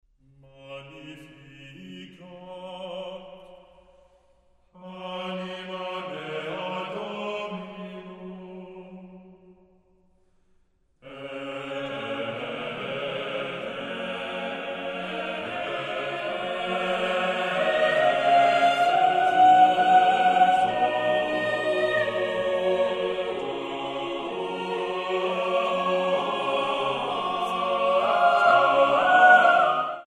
sweeping melody and carefully graded textures